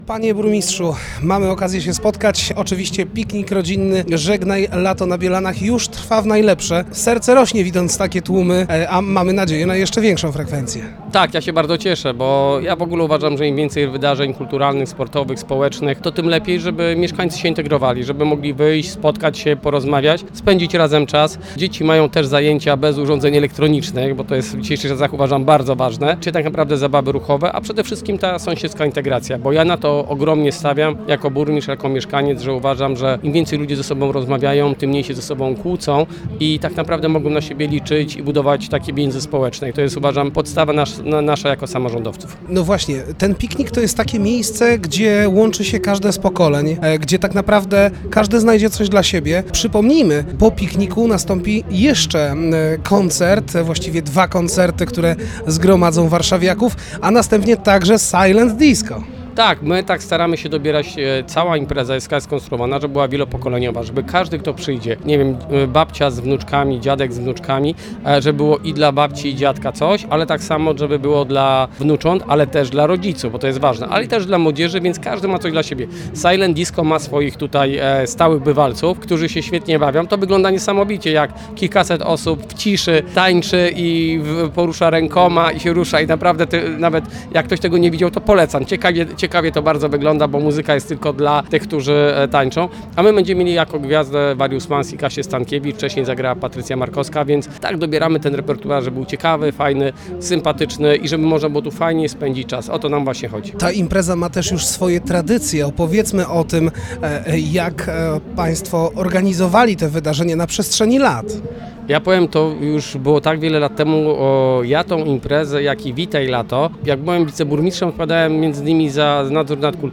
Jak dodał samorządowiec, Bielany są miejscem, w którym oferta wydarzeń dla małych i dużych jest bardzo bogata.
burmistrz-5.mp3